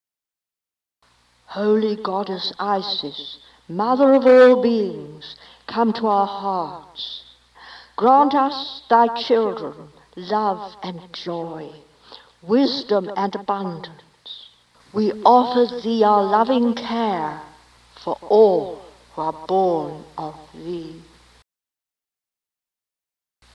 FOI Daily Prayer: